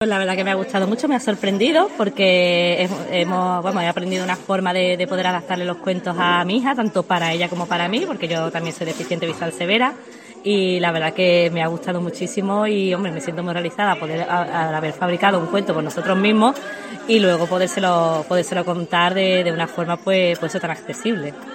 Y una que se estrenaba, y es también afiliada,